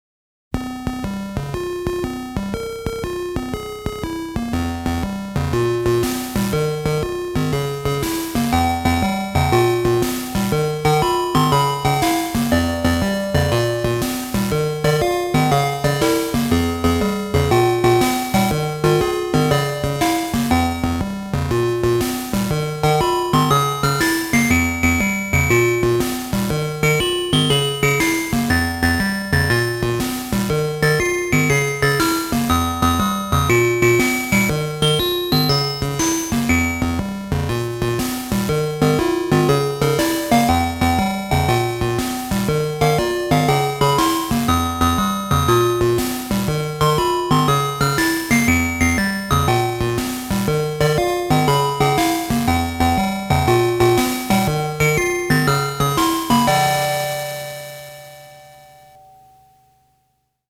Atari-ST Tunes